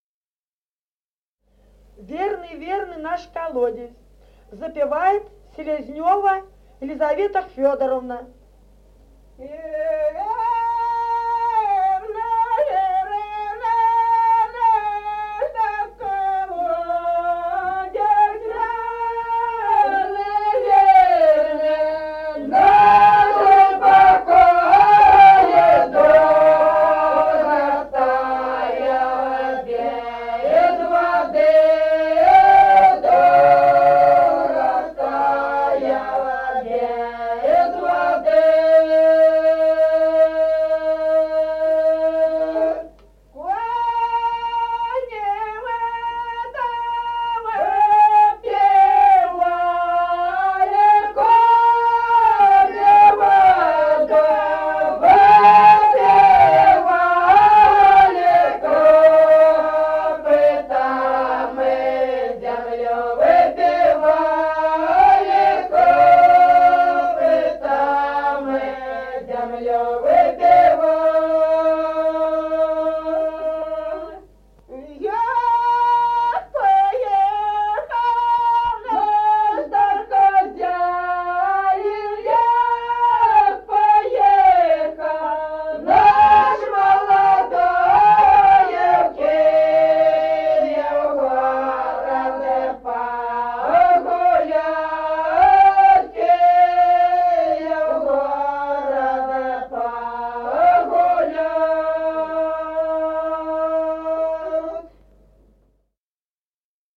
Народные песни Стародубского района «Верный наш колодезь», карагодная.
с. Остроглядово.